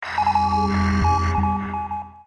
星际争霸雷达声音2 - 素材仓库